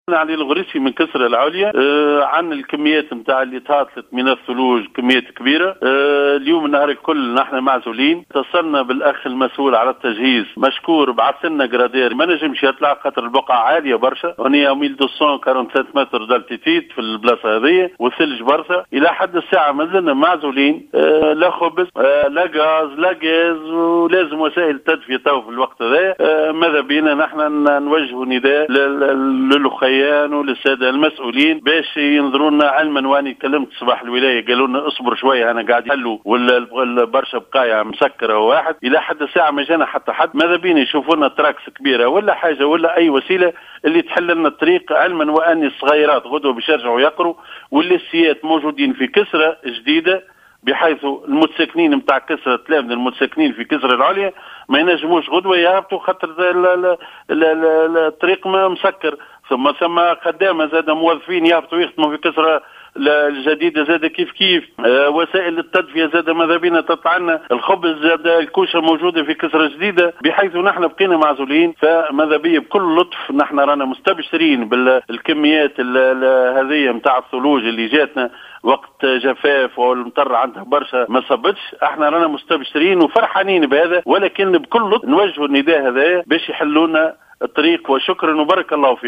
وجه مواطن أصيل منطقة كسرى العليا من ولاية سليانة نداء استغاثة عبر الجوهرة "اف ام" إلى المسؤولين لفتح الطريق الذي تراكمت فيه الثلوج مما تسبب في عزلتهم عن المناطق المجاورة .